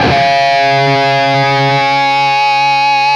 LEAD C#2 CUT.wav